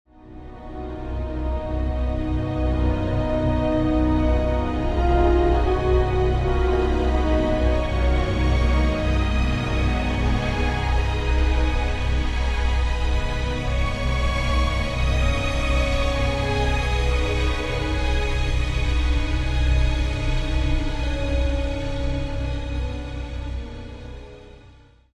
a dream-like soundtrack